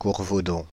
Courvaudon (French pronunciation: [kuʁvodɔ̃]
Fr-Courvaudon.ogg.mp3